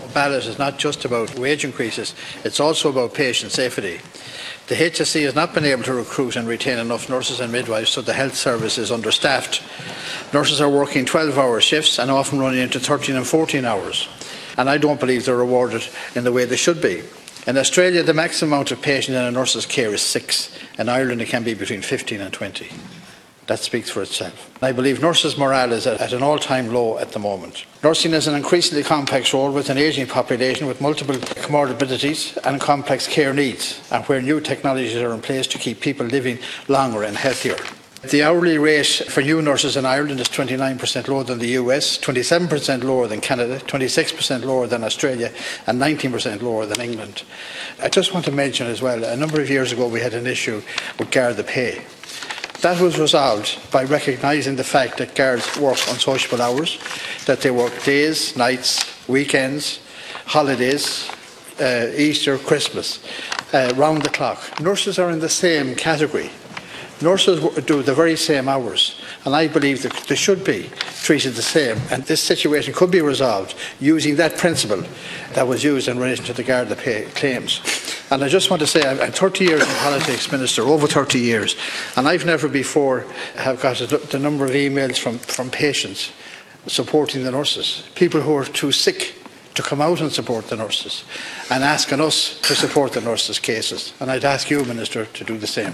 Speaking in the Dail, Deputy Scanlon says the dispute could be resolved with the same principle afforded to Gardai: